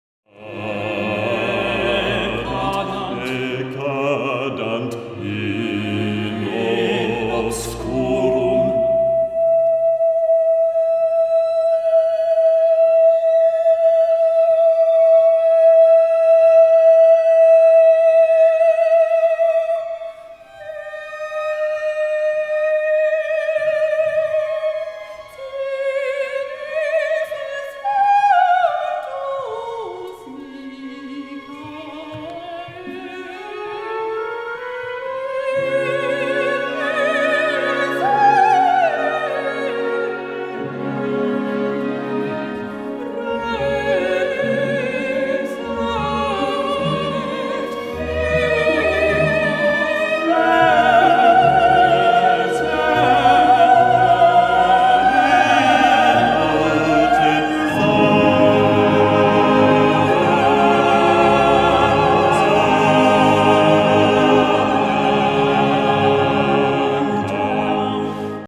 in Harpstedt